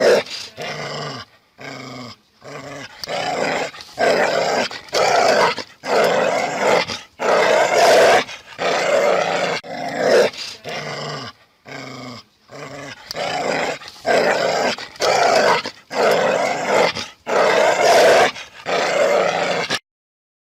Dog Attack